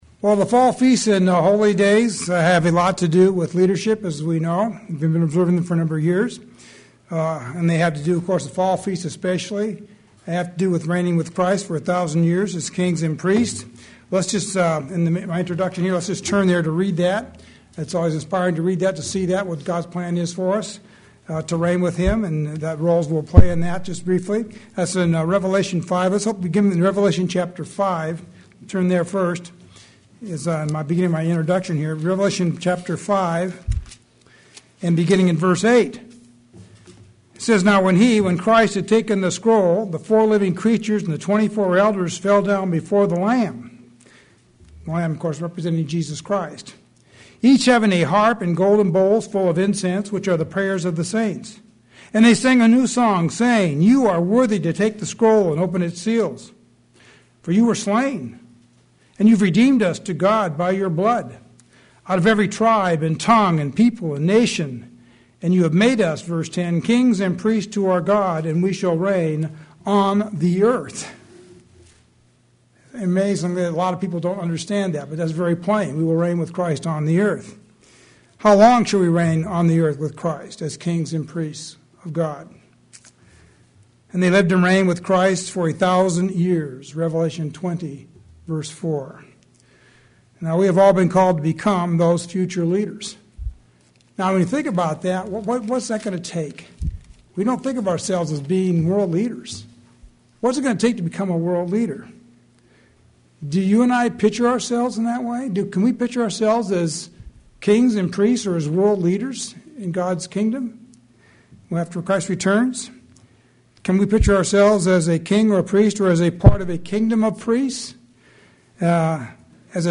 In this sermon we will look at four essential character traits required of a leader.